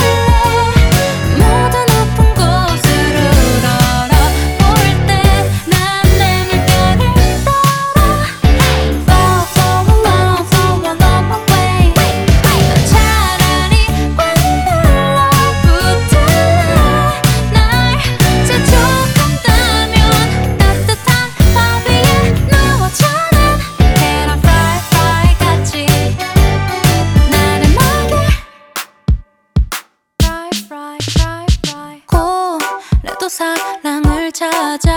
Жанр: Фолк-рок